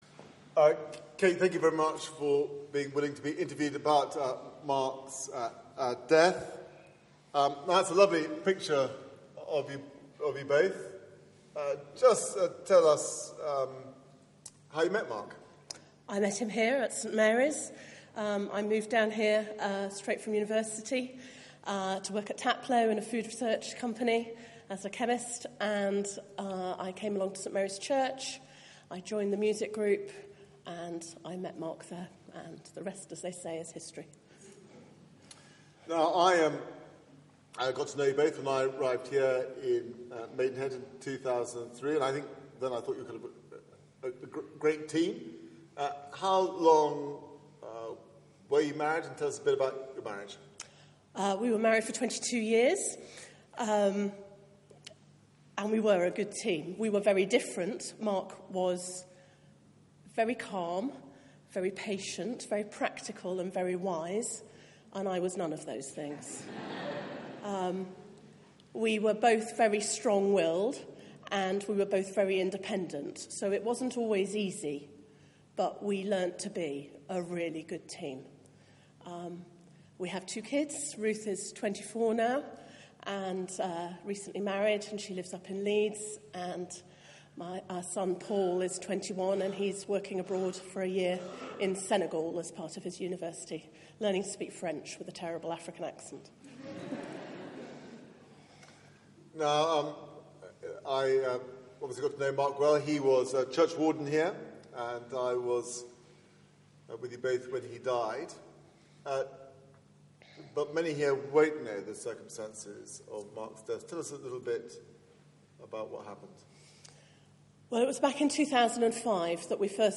Media for 9:15am Service on Sun 06th Mar 2016 09:15 Speaker
Sermon